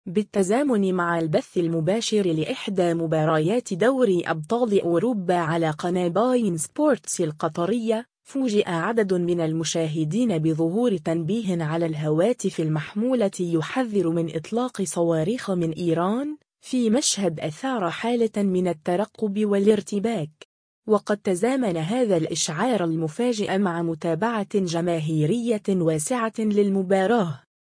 تنبيه على الهواتف بشأن إطلاق صواريخ من إيران خلال البث المباشر لدوري أبطال أوروبا على قناة beIN Sports القطرية